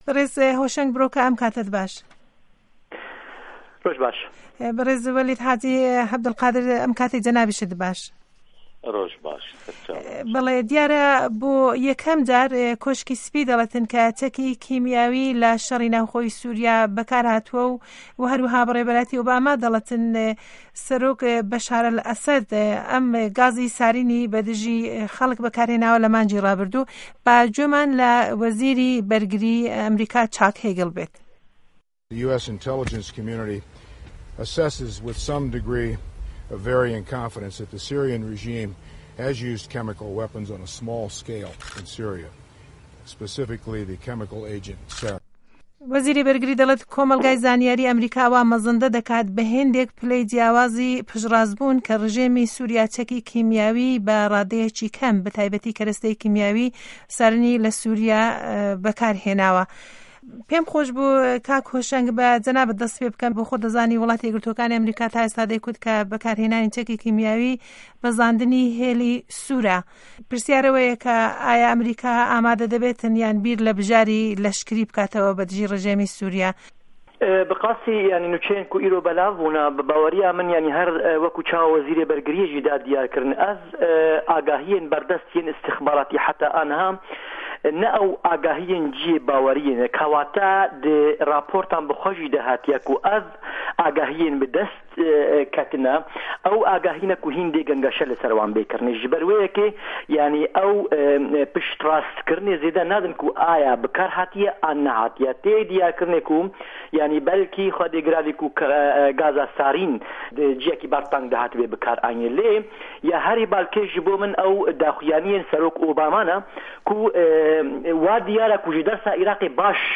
مێزگرد: به‌کارهێنانی چه‌کی کیمیاوی له لایه‌ن‌ حکومه‌تی سوریا و بژاره‌کانی به‌رده‌م ئه‌مه‌ریکا